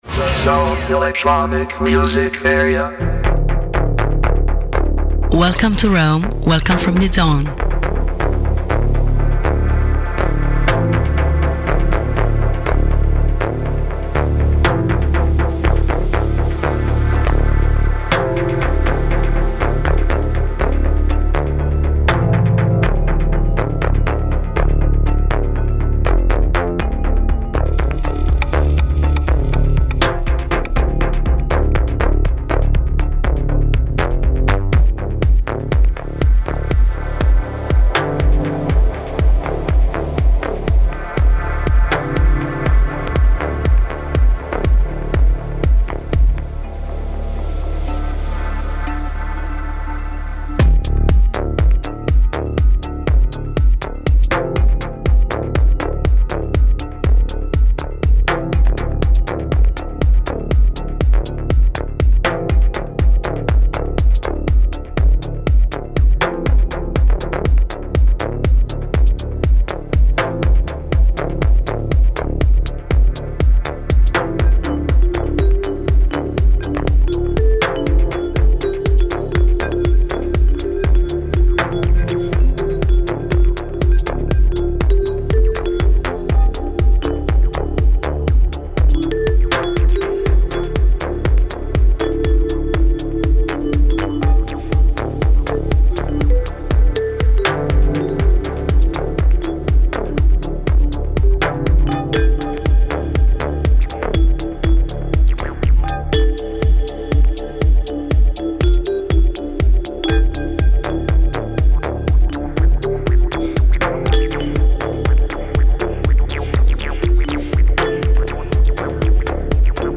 DJSET IN DIRETTA DI